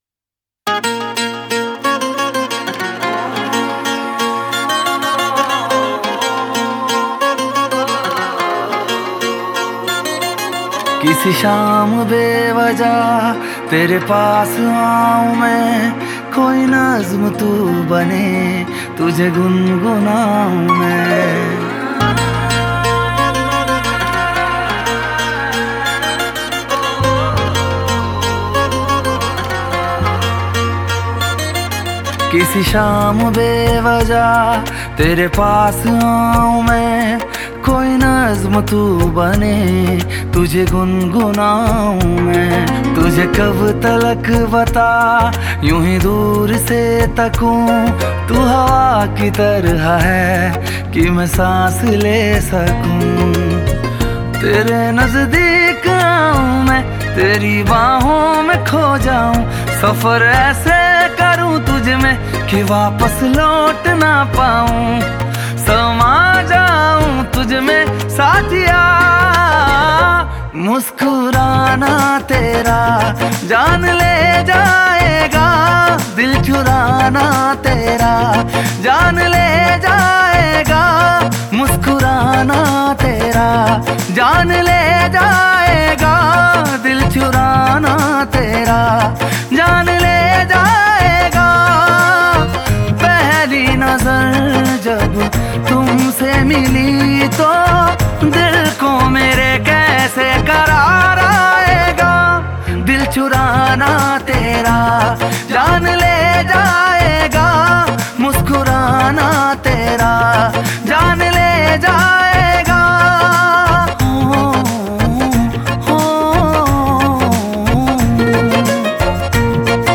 IndiPop Music Album